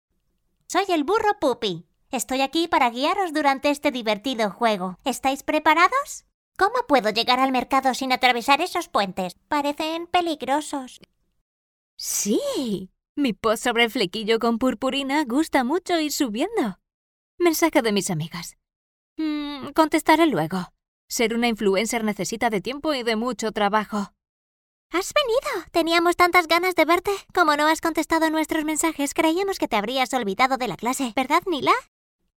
Kommerziell, Zugänglich, Vielseitig, Warm, Sanft
Persönlichkeiten